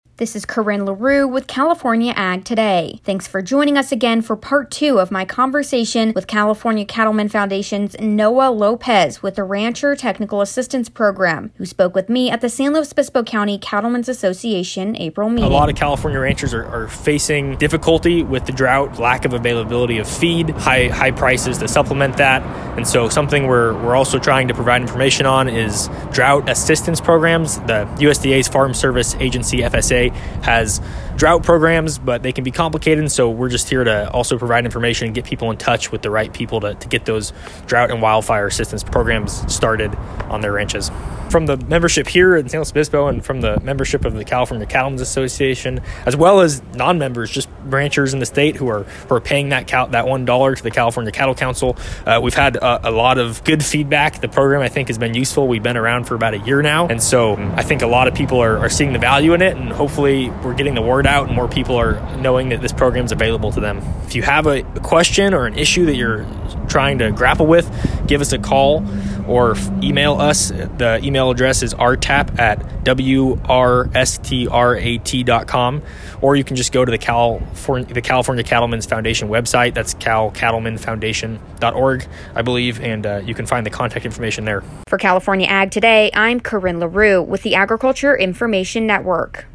who spoke with me at the San Luis Obispo County Cattlemen’s Association April Meeting.